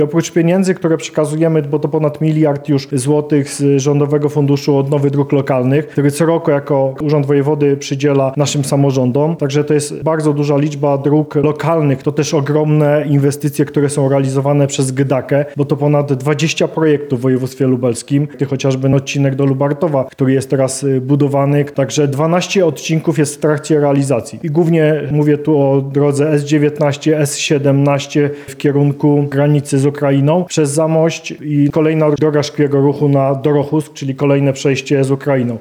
Polskie Stronnictwo Ludowe podsumowało w Lublinie dwa lata pracy w koalicji rządowej.